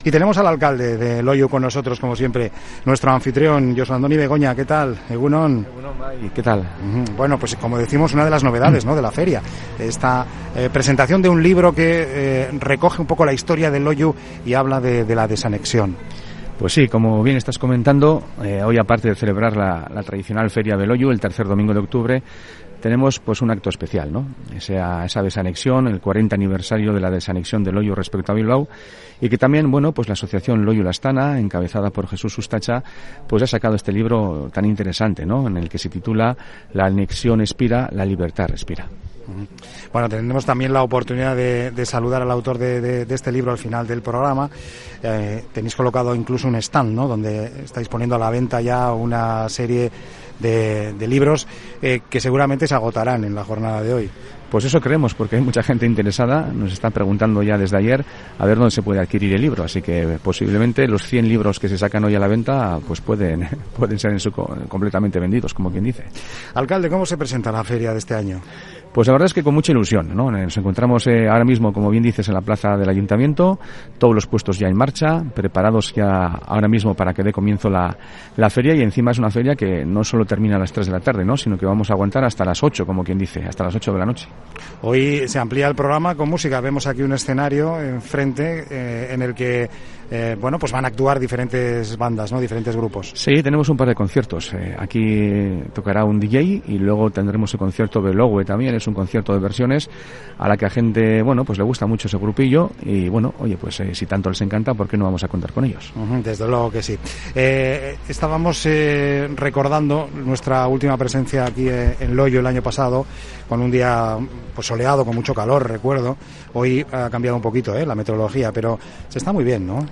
Onda Vasca Bizkaia en directo
Un año más, Onda Vasca se ha sumergido en la fiesta con una programación especial, dando voz a los diferentes actores y protagonistas de la feria. Por el set de Onda Vasca se ha pasado el alcalde de la localidad del Txorierri, Josu Andoni Begoña, que además de dar cuenta de los aspectos fundamentales de la jornada, ha hecho repaso de la actualidad del pueblo, relatando cuáles son los retos fundamentales para Loiu en la presente legislatura.